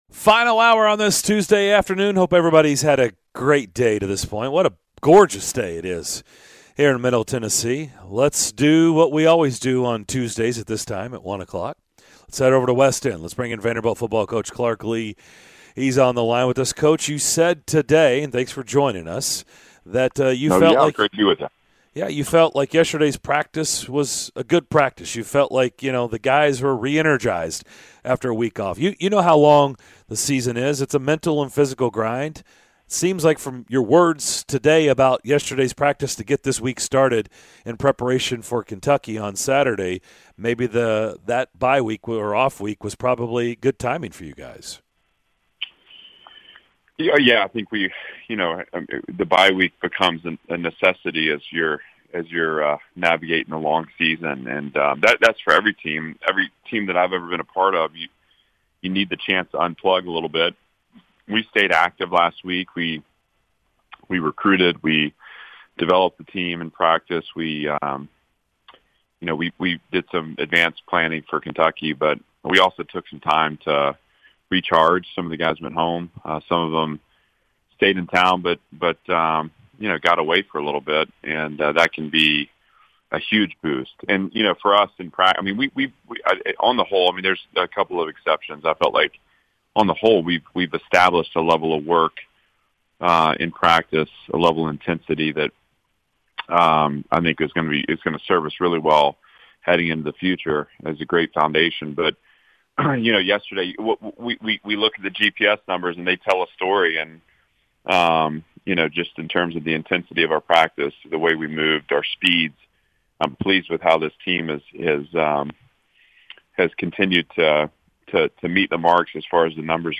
Vanderbilt Head Football Coach Clark Lea joined the DDC to discuss recruiting, his team's bye week, the upcoming game against Kentucky and more!